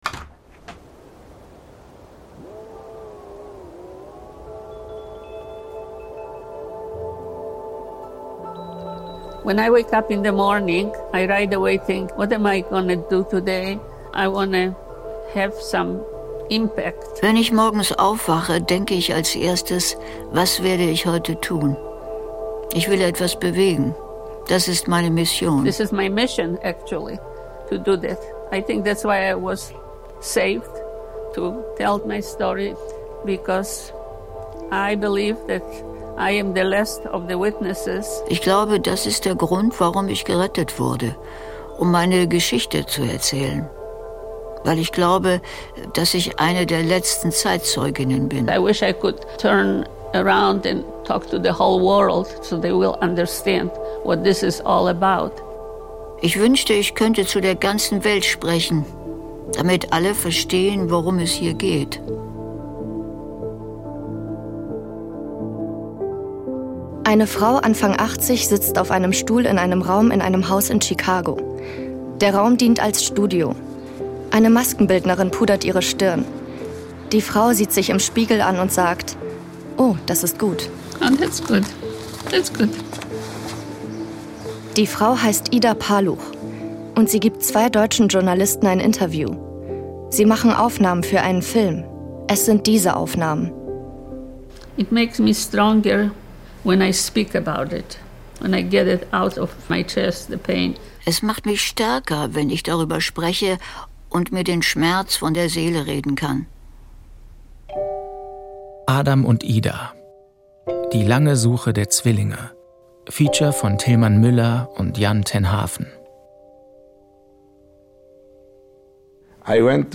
Die lange Suche der Zwillinge ist ein Feature